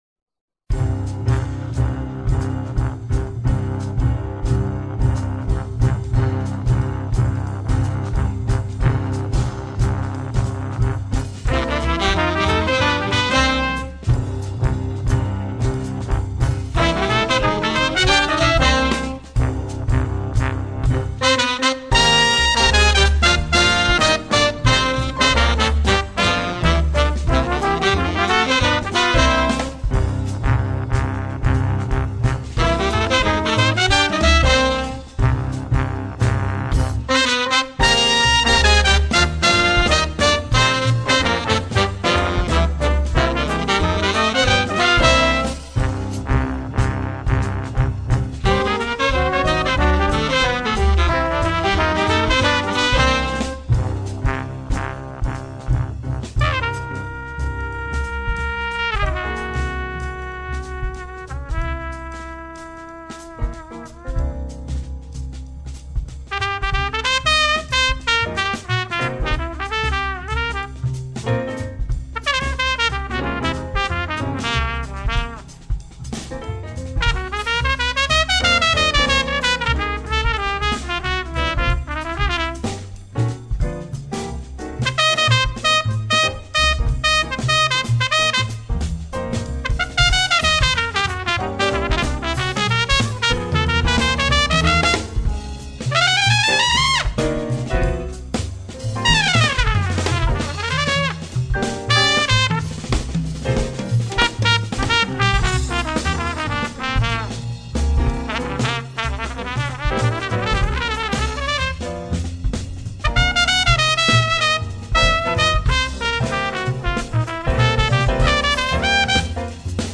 consummately crafted, clean jazz
the trombone
On the trumpet this time